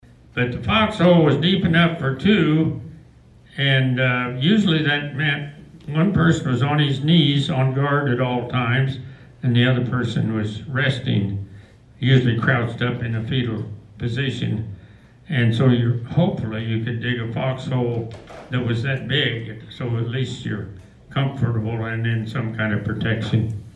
Stories were shared during a panel discussion Saturday at the American Legion.